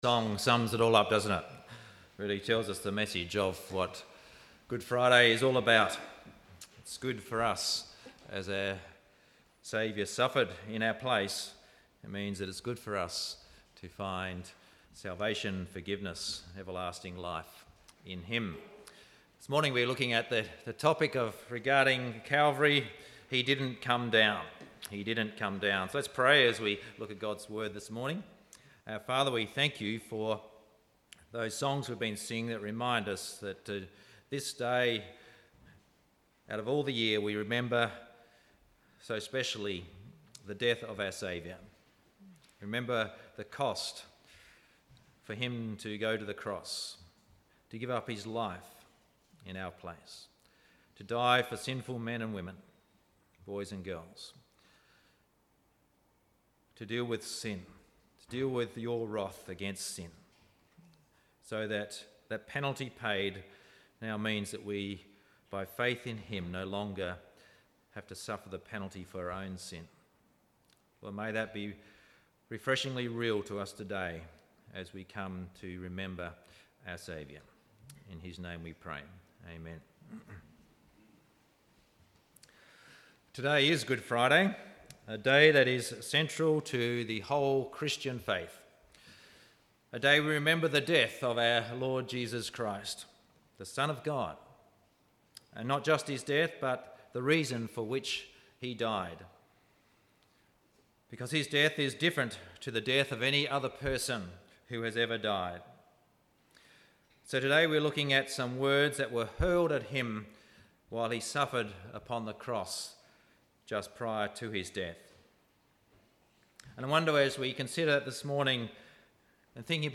Good Friday Service Audio from 30/03/18